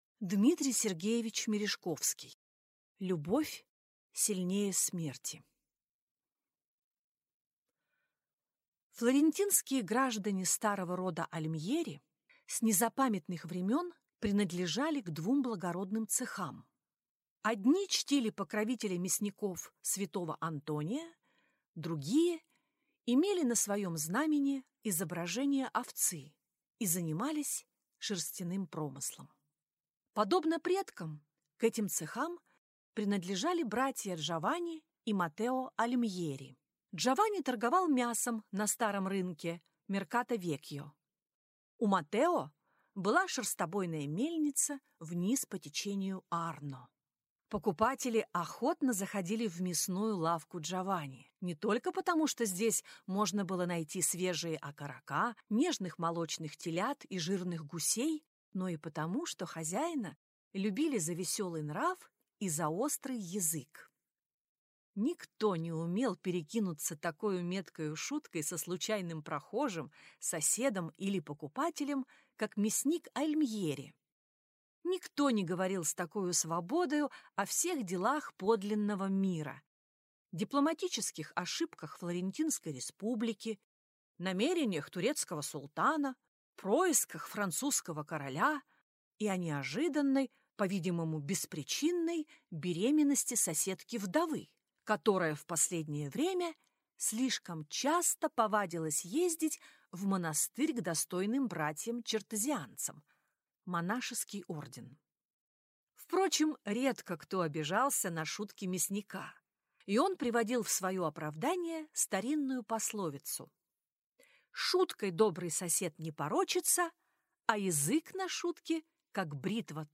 Аудиокнига Любовь сильнее смерти | Библиотека аудиокниг